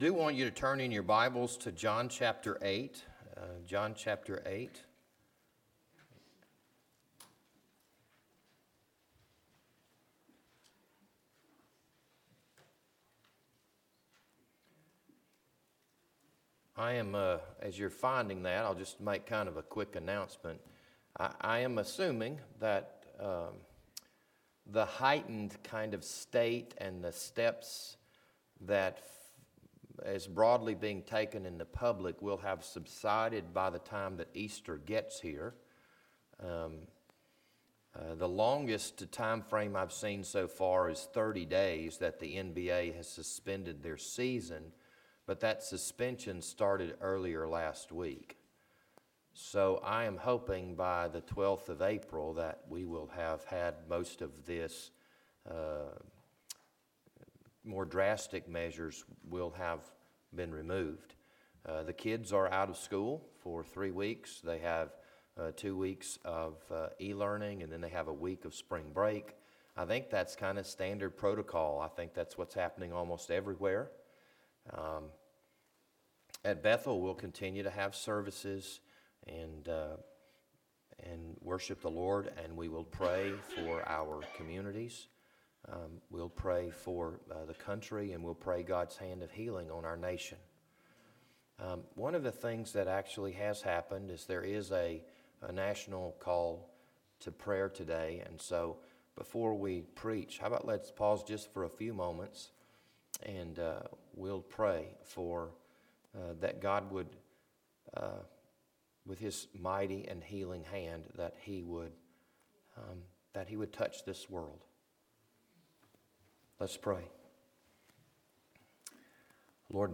This Sunday morning sermon was recorded on March 15th, 2020.